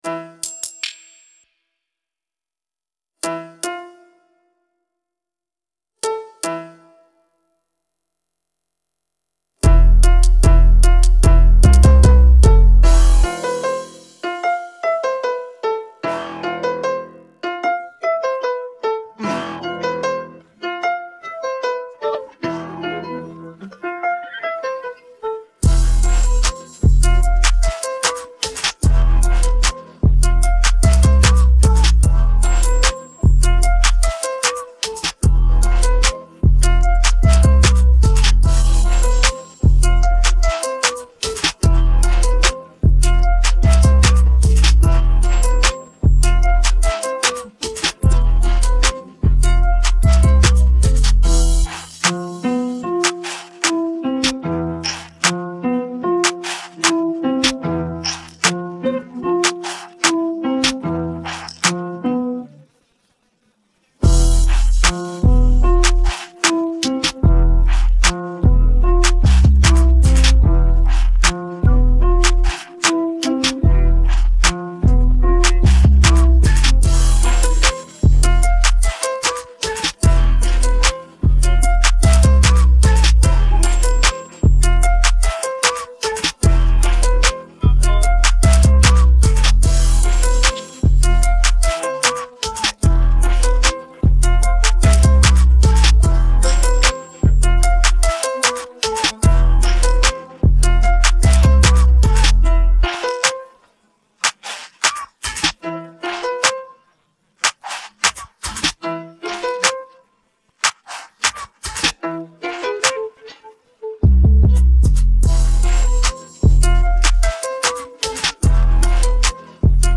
Taustamusiikki